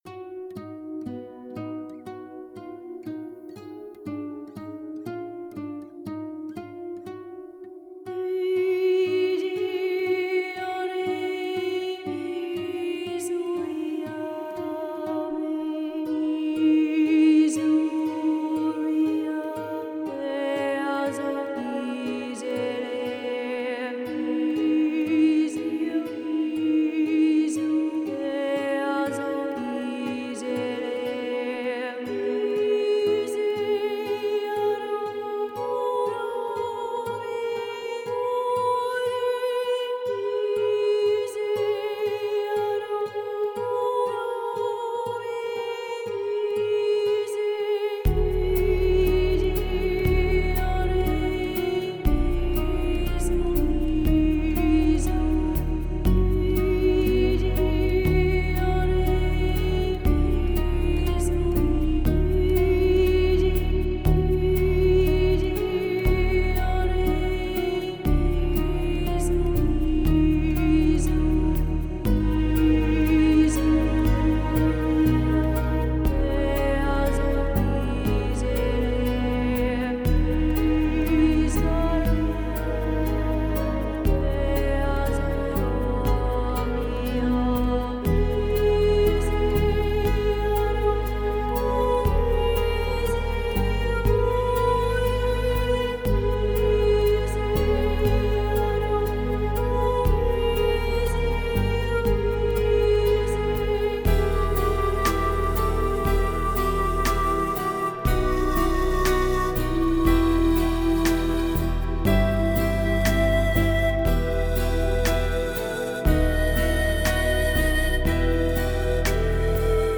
Музыка релакс Relax Музыка нью эйдж New age Нью эйдж